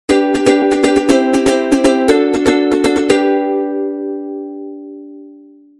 Звуки укулеле